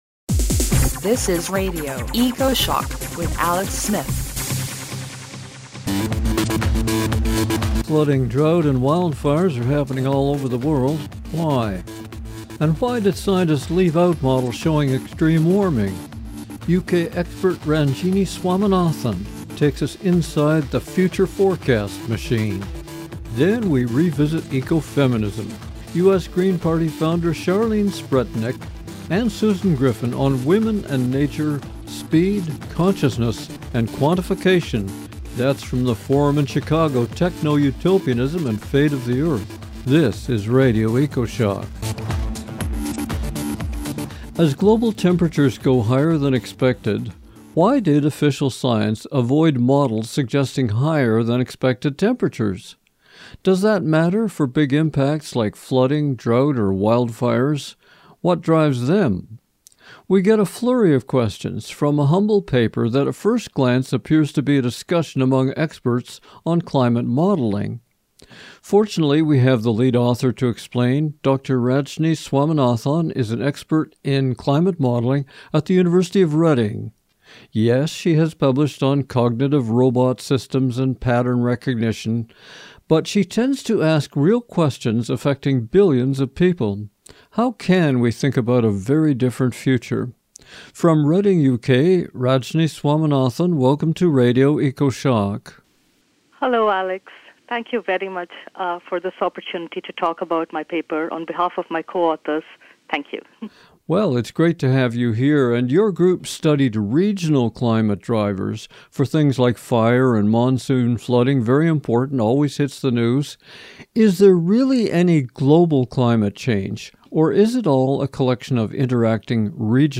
Radio Ecoshock broadcast affiliate version (58 minutes, 54MB, 128Kbps, stereo mp3) of our Weekly 1 hour program featuring the latest science, authors, issues - from climate change, oceans, forests, pollution, Peak Oil, the economy, and peace.